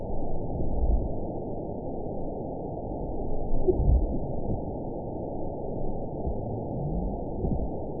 event 920181 date 03/01/24 time 23:43:29 GMT (1 year, 9 months ago) score 8.74 location TSS-AB07 detected by nrw target species NRW annotations +NRW Spectrogram: Frequency (kHz) vs. Time (s) audio not available .wav